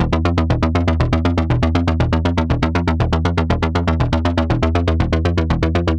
Index of /musicradar/dystopian-drone-samples/Droney Arps/120bpm
DD_DroneyArp4_120-E.wav